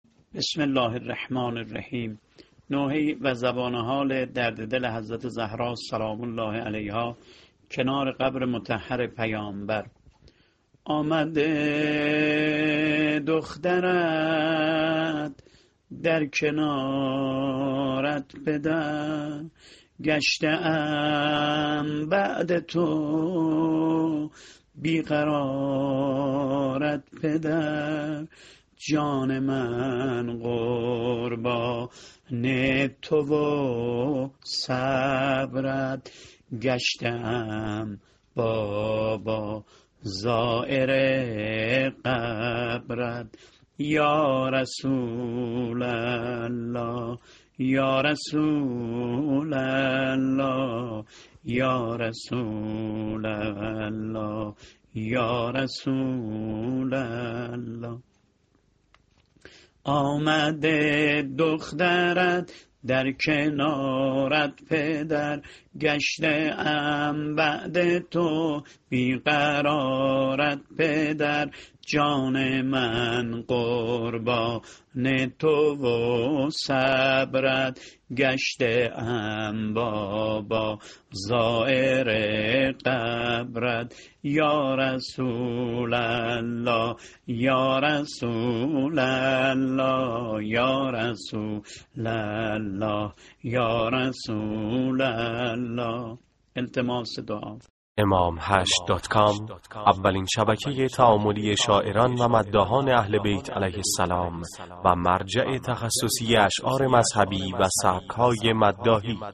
متن شعر و نوحه درددل کنارقبر پیامبر(ص) فاطمیه 98 -(آمده دخترت در کنارت پدر )